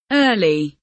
early kelimesinin anlamı, resimli anlatımı ve sesli okunuşu